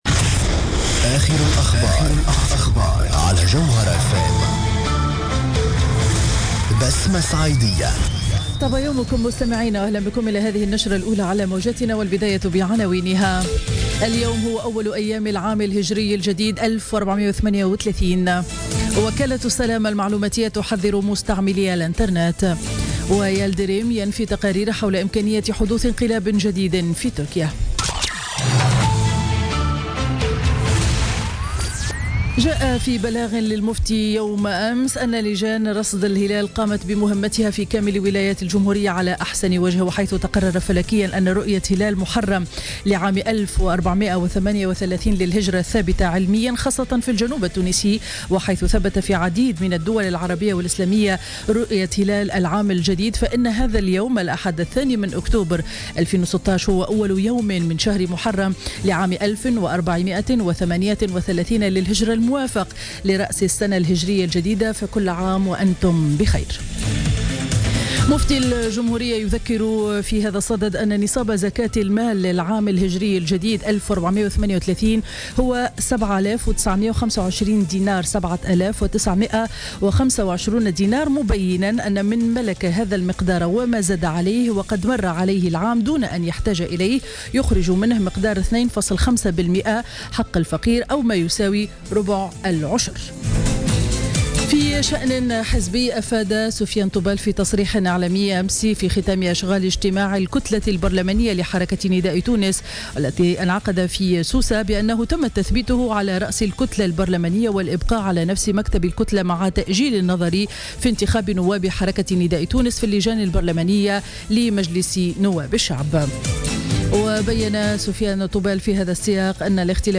نشرة أخبار السابعة صباحا ليوم الأحد 2 أكتوبر 2016